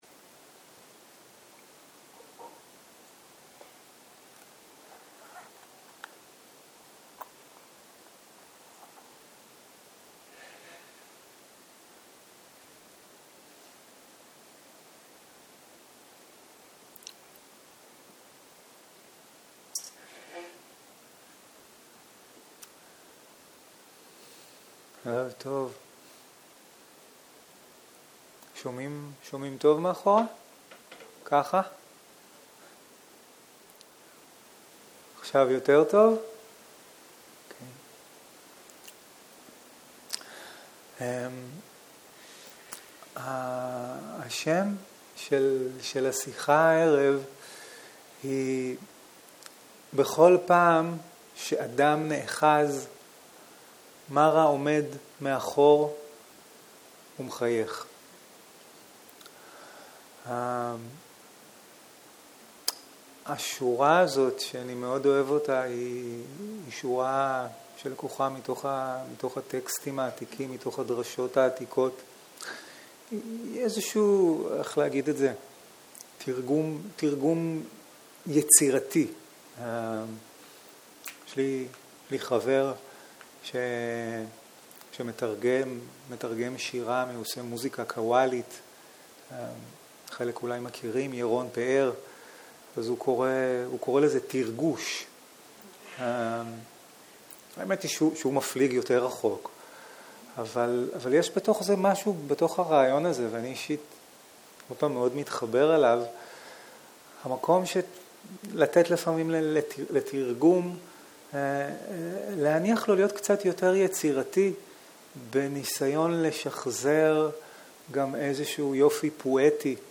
ערב - שיחת דהרמה - בכל פעם שאדם נאחז, מארא עומד מאחוריו ומחייך - שיחה 9